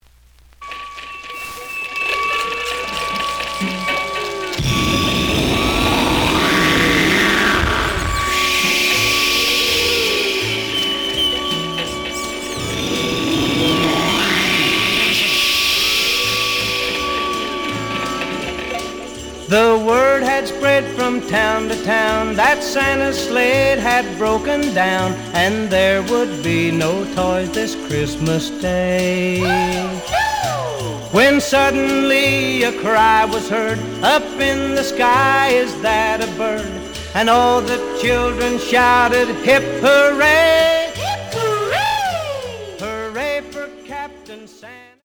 The audio sample is recorded from the actual item.
●Genre: Rhythm And Blues / Rock 'n' Roll
Some noise on beginning of B side, but almost good.)